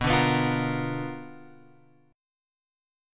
B Minor Major 7th Flat 5th